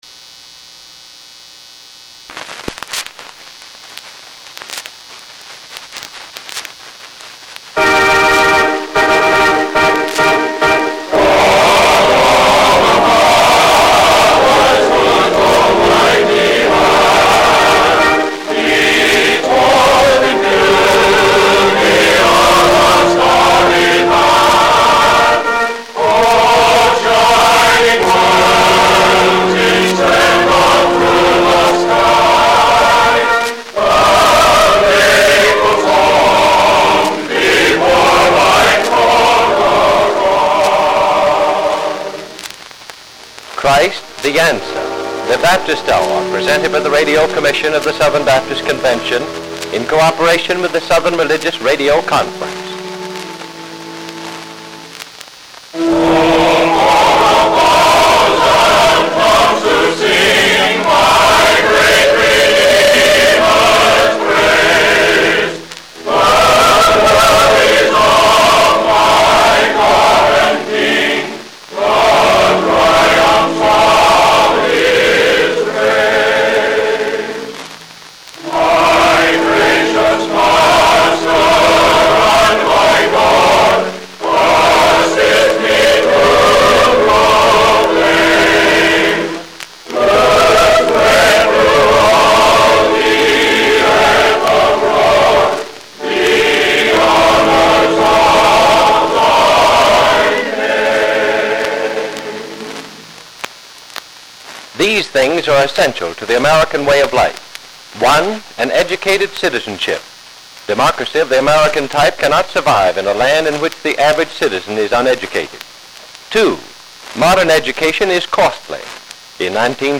Monroe Elmon Dodd delivered the first message on the half-hour evangelistic program.
The programs were offered transcribed to independent radio stations, and 180 stations scheduled the broadcasts.